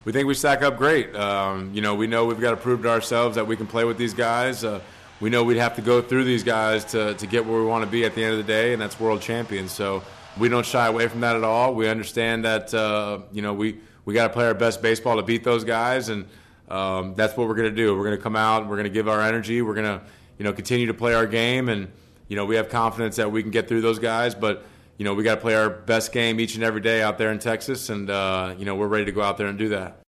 The Padres understand the challenge they have in this series but they are not scared. First baseman Eric Hosmer addressed the media on how the team feels about their opponent.